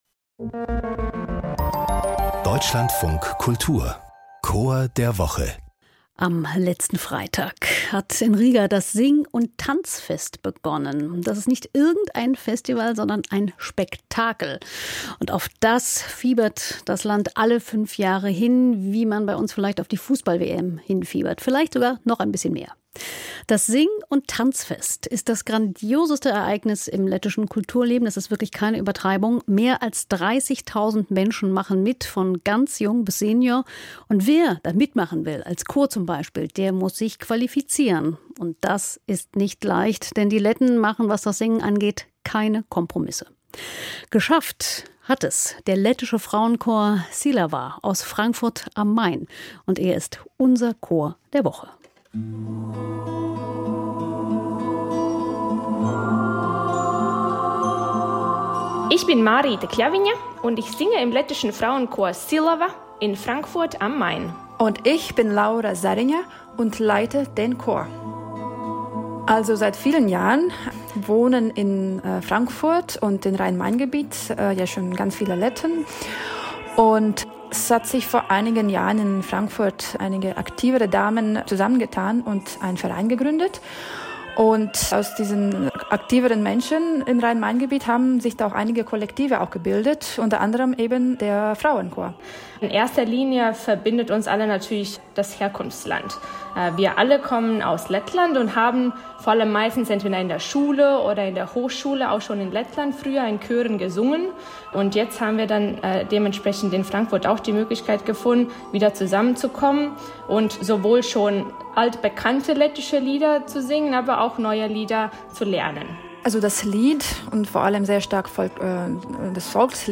Lettischer Frauenchor Silava